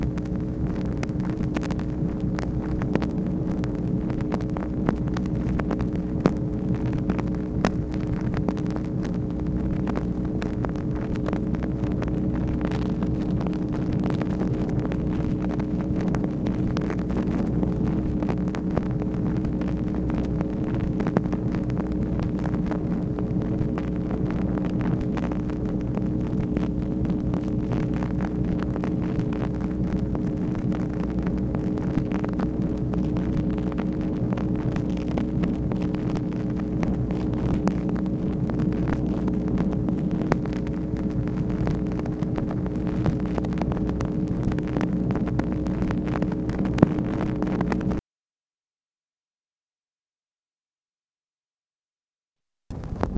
MACHINE NOISE, November 3, 1964
Secret White House Tapes | Lyndon B. Johnson Presidency